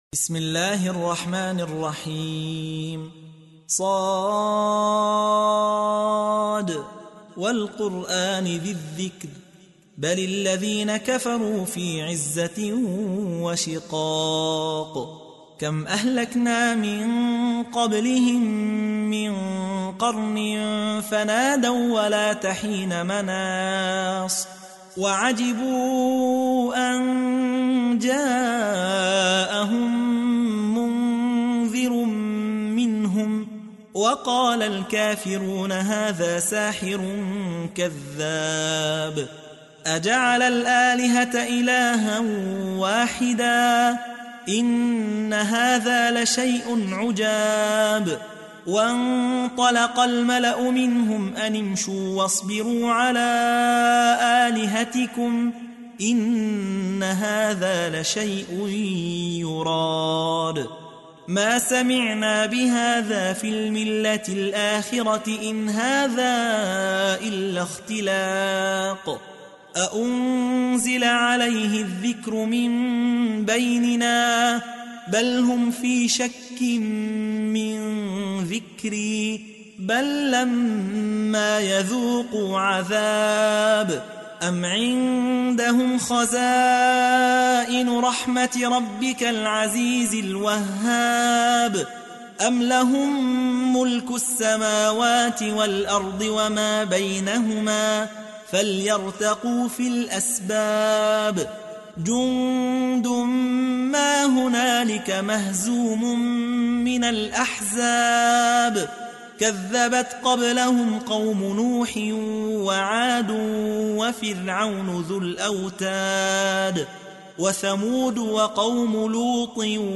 تحميل : 38. سورة ص / القارئ يحيى حوا / القرآن الكريم / موقع يا حسين